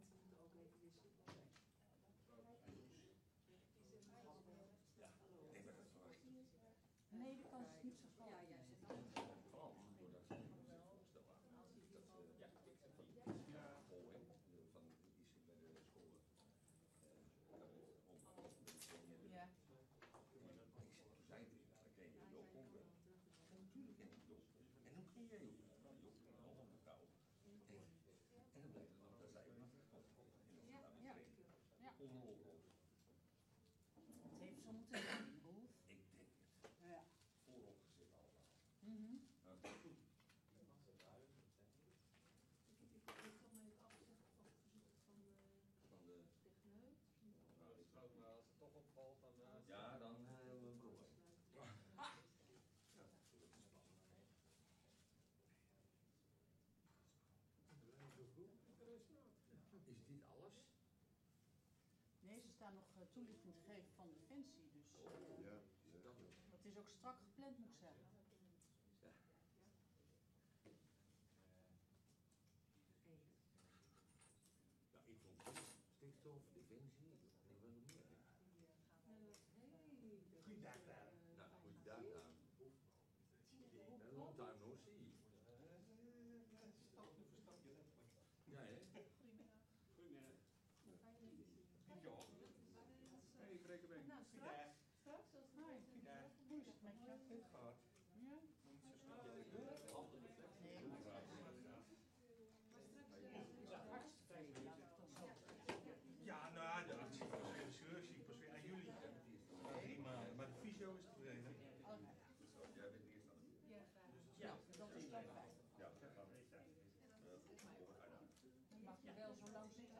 Statencommissie Economie, Mobiliteit en Samenleving (EMS) 25 juni 2025 15:30:00, Provincie Flevoland
Download de volledige audio van deze vergadering